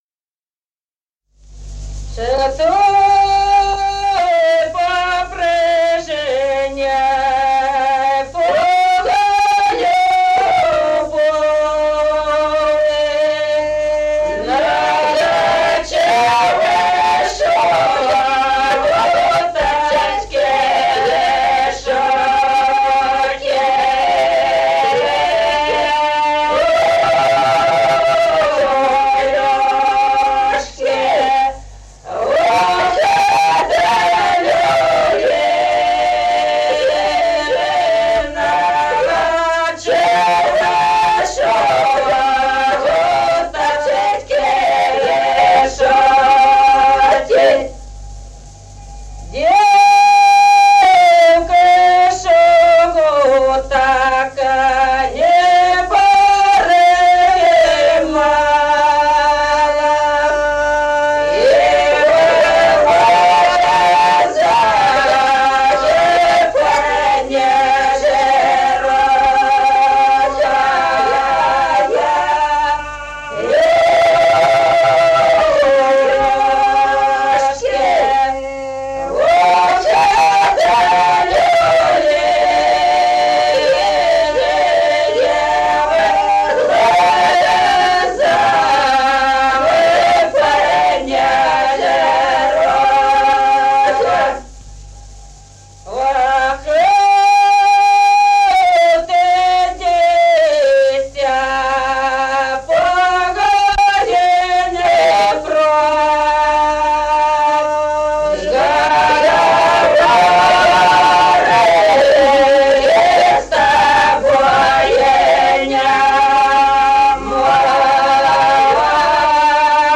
Народные песни Стародубского района «Чтой по прежней по любви», карагодная.
с. Остроглядово.